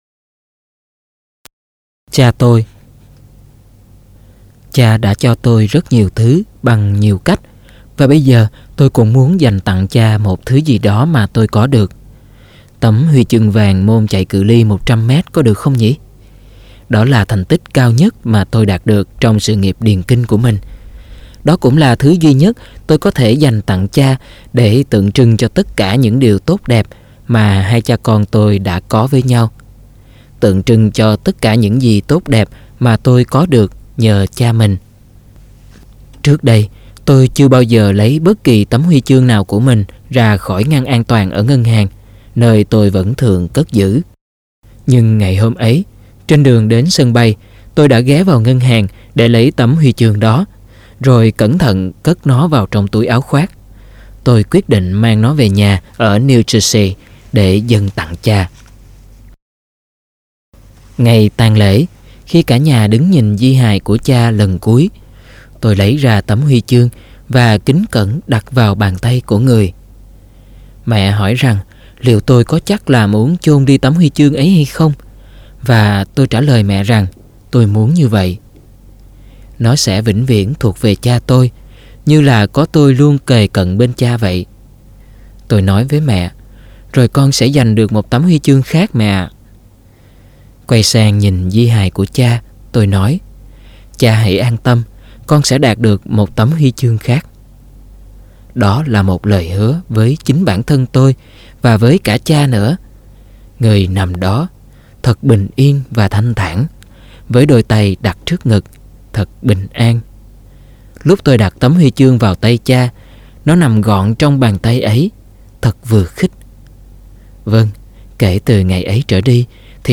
Sách nói Chicken Soup 3 - Chia Sẻ Tâm Hồn Và Quà Tặng Cuộc Sống - Jack Canfield - Sách Nói Online Hay